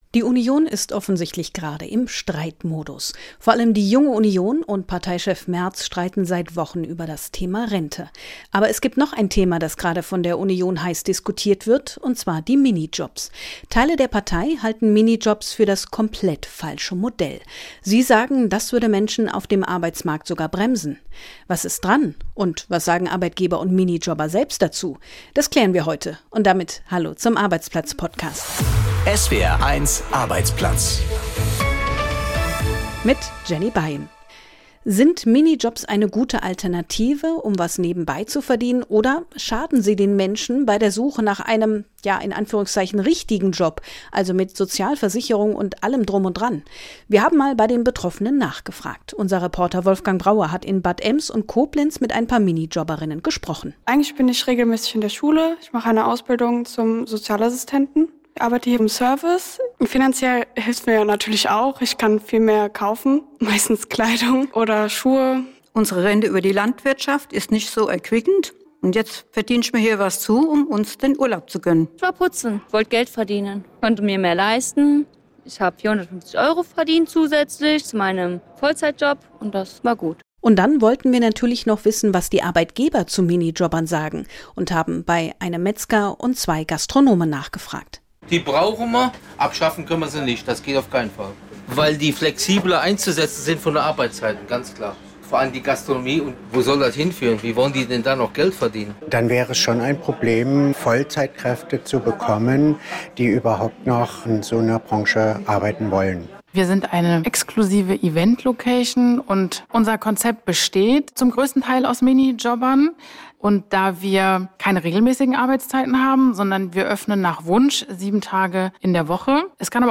Beschreibung vor 5 Monaten Wie blicken Minijobber und ihre Chefs auf die neu entfachte Debatte? ++ Was muss Teil einer nachhaltigen Reform des Konzepts sein? Gespräch